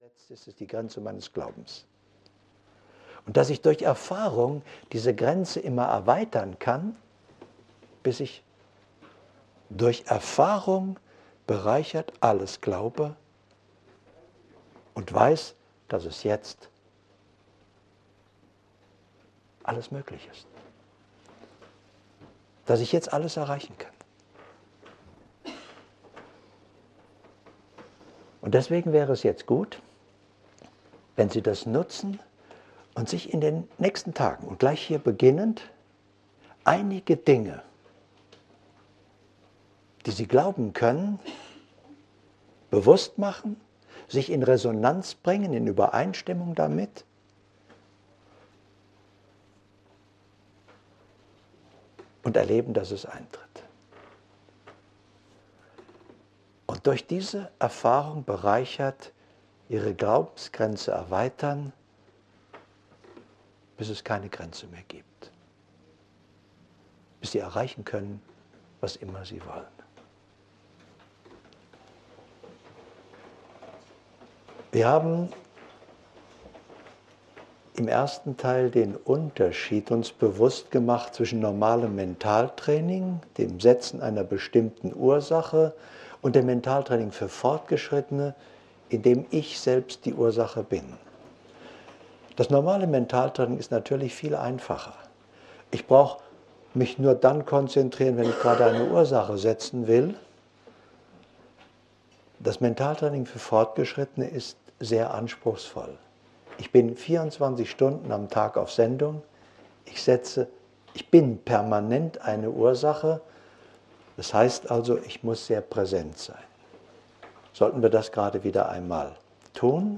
Mentaltraining Kursus: Veränderung geschieht jetzt - Teil 3 - Hörbuch